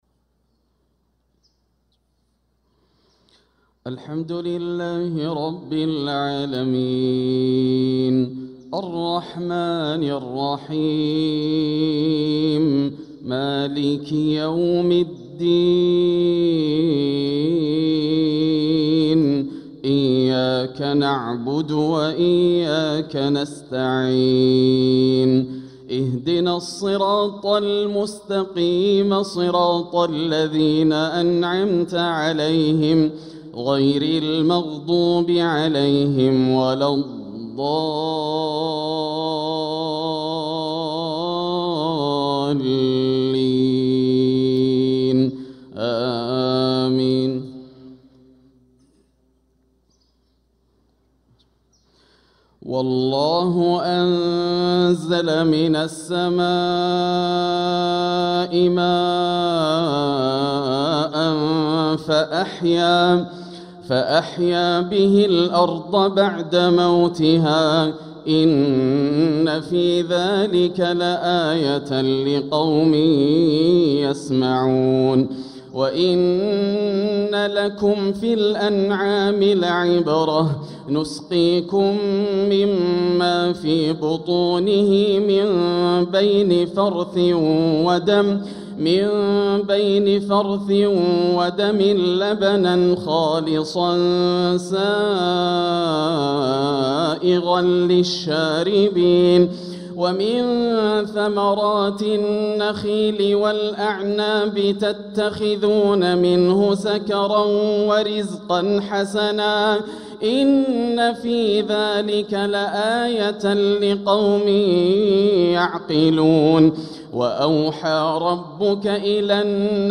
صلاة الفجر للقارئ ياسر الدوسري 2 صفر 1446 هـ
تِلَاوَات الْحَرَمَيْن .